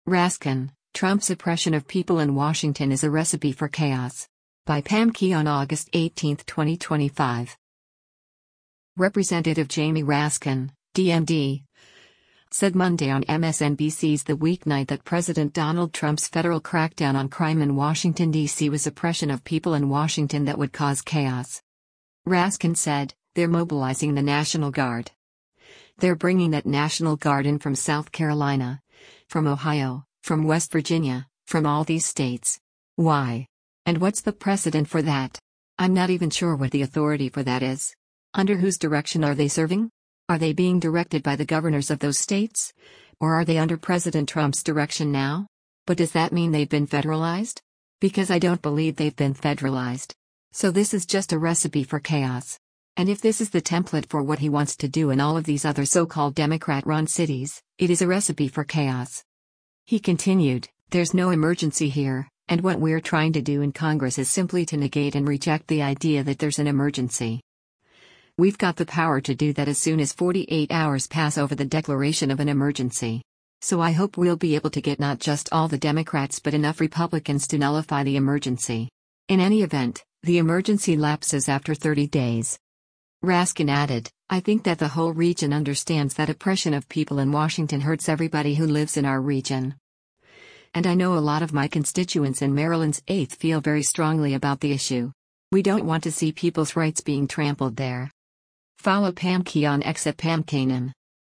Representative Jamie Raskin (D-MD) said Monday on MSNBC’s “The Weeknight” that President Donald Trump’s federal crackdown on crime in Washington, D.C. was “oppression of people in Washington” that would cause “chaos.”